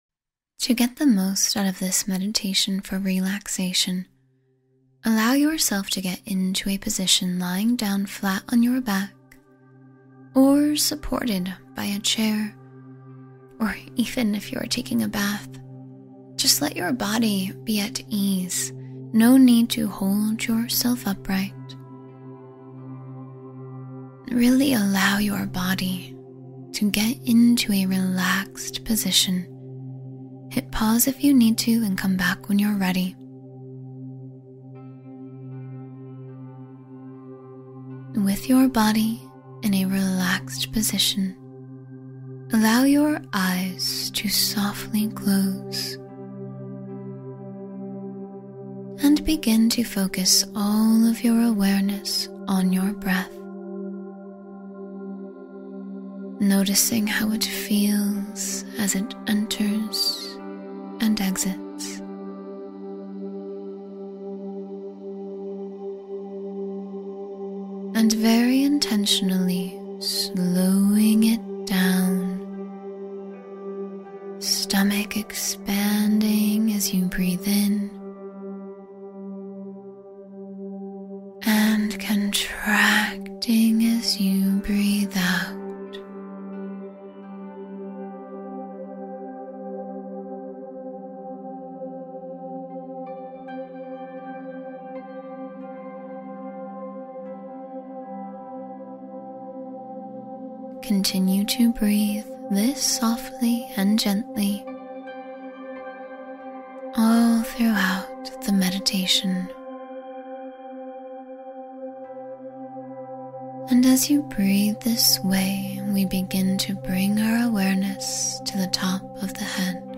Deep Relaxation for Mind, Body, and Spirit — Guided Meditation for Full Restoration